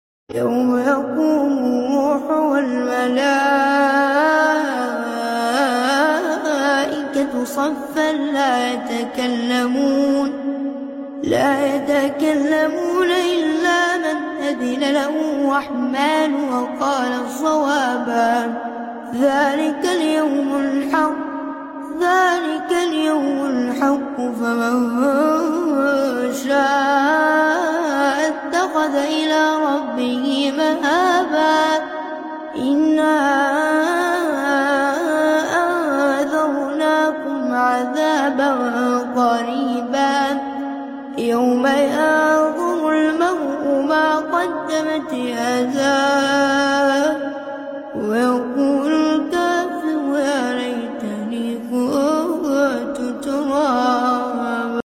تلاوة مؤثرة